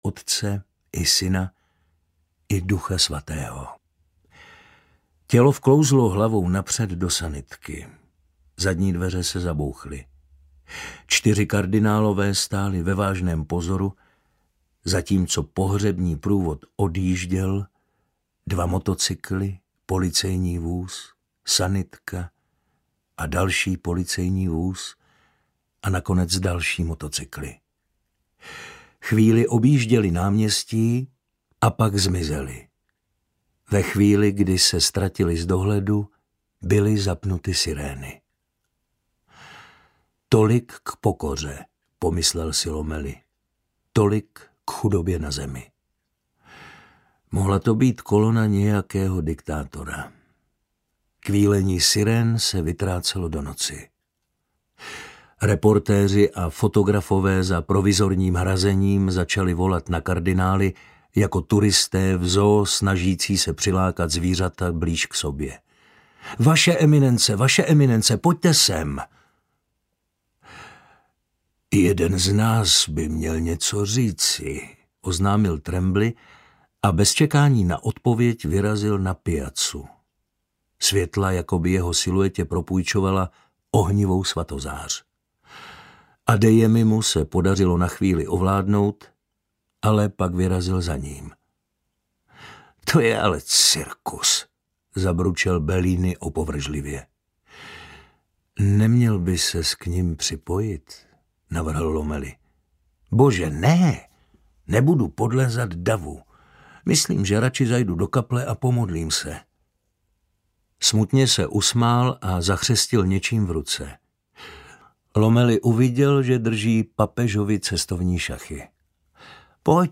Konkláve audiokniha
Audiokniha Konkláve, kterou napsal Robert Harris.
Ukázka z knihy